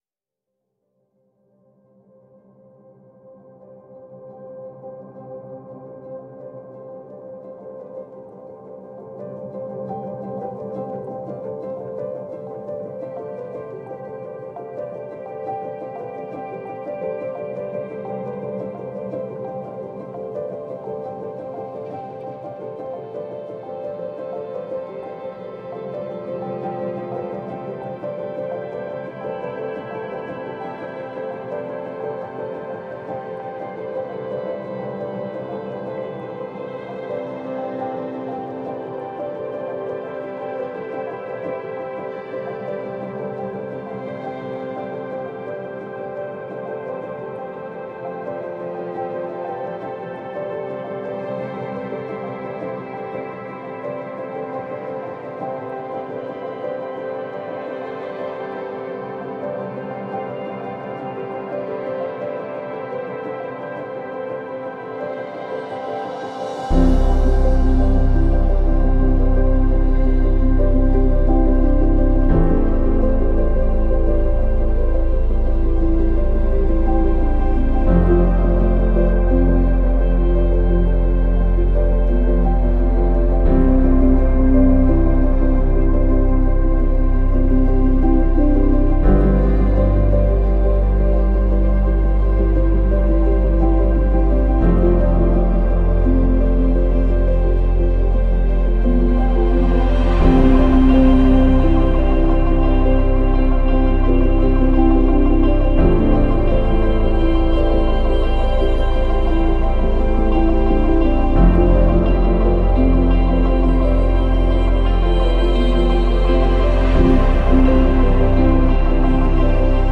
موسیقی بی کلام امبینت